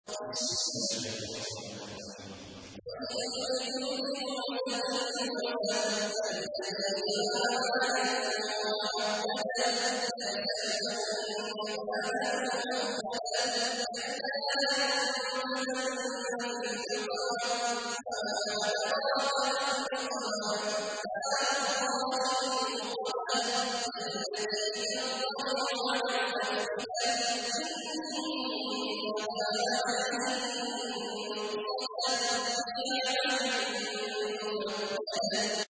تحميل : 104. سورة الهمزة / القارئ عبد الله عواد الجهني / القرآن الكريم / موقع يا حسين